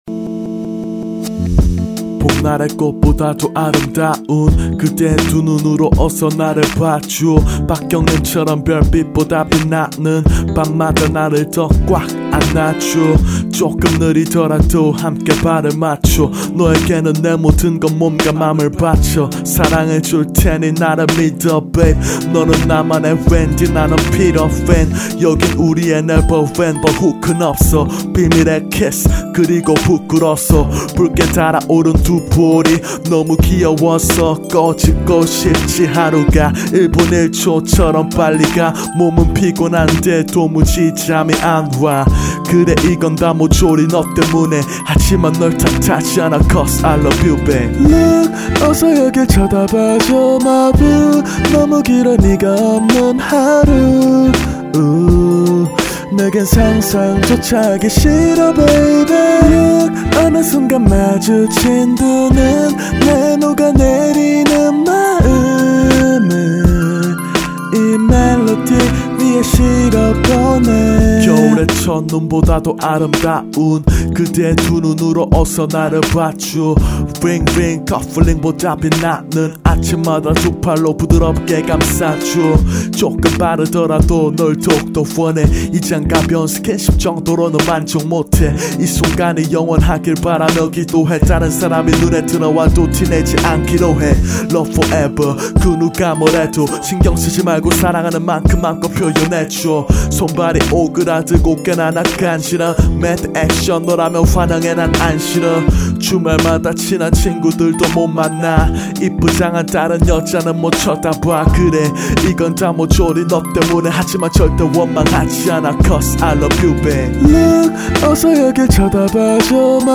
• [국내 / REMIX.]